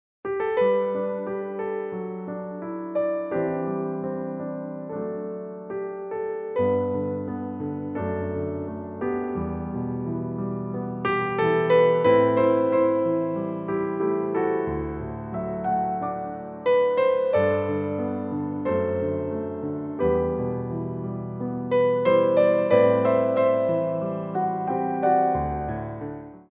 4/4 (16x8)